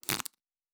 Fantasy Interface Sounds
Cards Shuffle 1_03.wav